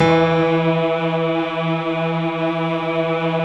SI1 PIANO05R.wav